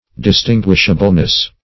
Search Result for " distinguishableness" : The Collaborative International Dictionary of English v.0.48: Distinguishableness \Dis*tin"guish*a*ble*ness\, n. The quality of being distinguishable.
distinguishableness.mp3